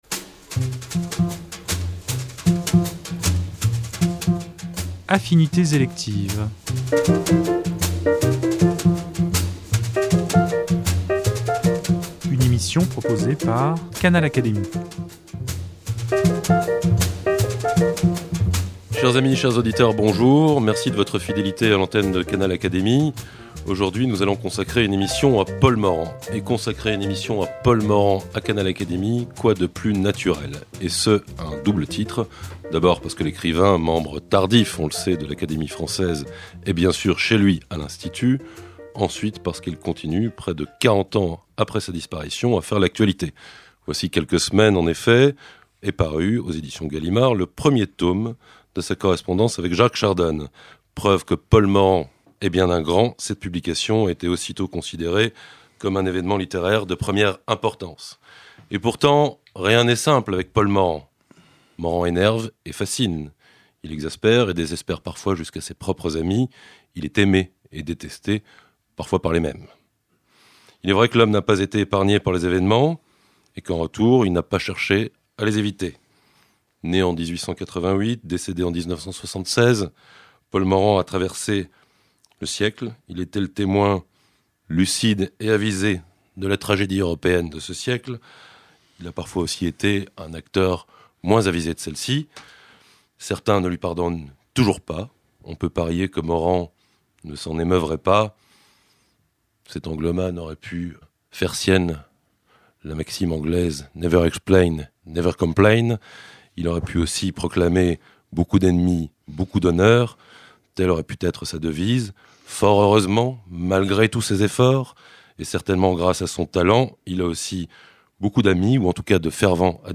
A l’occasion de la parution du premier tome de la Correspondance entre Morand et Chardonne, nos invités évoquent, en toute liberté, l’auteur de « L’Europe galante ». Au fil de leurs échanges, nous redécouvrons peu à peu l’étendue et les multiples facettes de son immense talent.